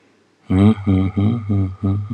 Vorgelesen von echten Menschen – nicht von Computern.